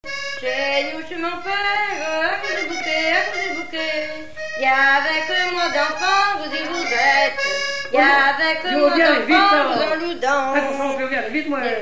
Genre laisse
Catégorie Pièce musicale inédite